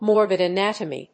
アクセントmórbid anátomy